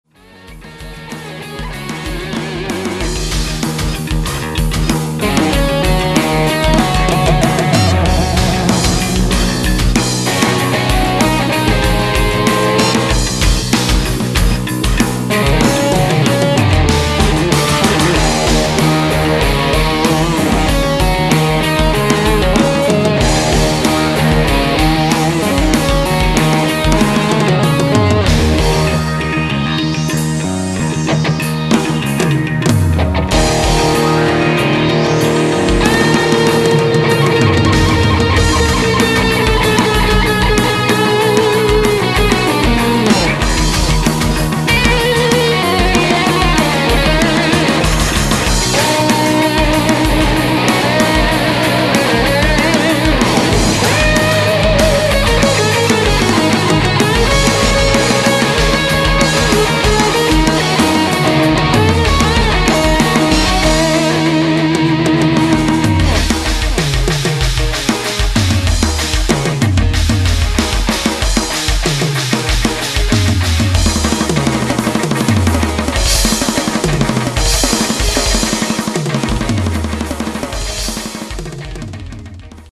Gesang
Drums
Bass
Keys
Gitarre